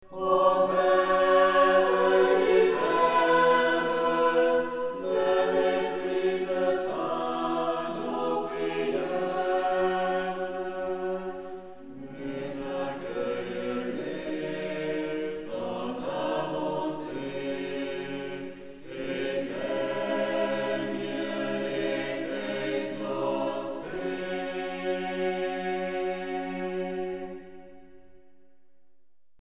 Deux offices des Laudes du temps ordinaire.